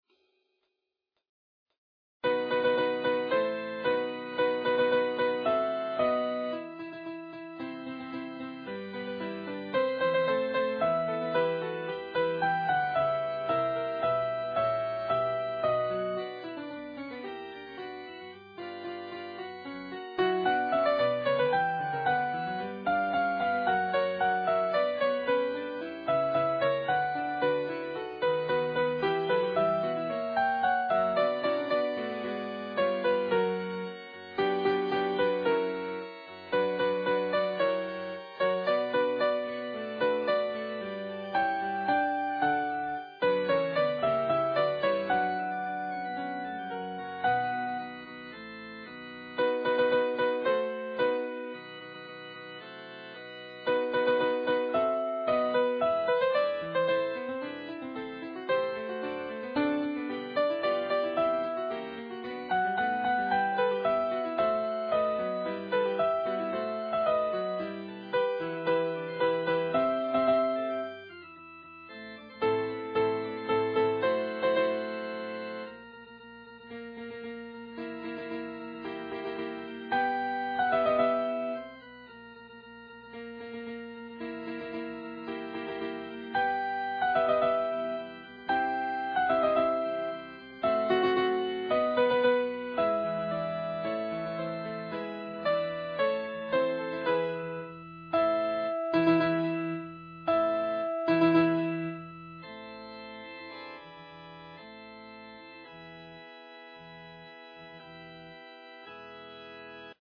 Les fichiers de travail mettent en relief au piano chacune des voix dans le contexte général ; ce sont des fichiers .mp3 qui peuvent être lus par un ordinateur, un lecteur mp3, ou directement gravés sur un CD audio.
Lorsque le choix en a été possible, le tempo des fichiers de travail est bien entendu un tempo de travail, et ne reflète pas nécessairement celui de notre interprétation finale...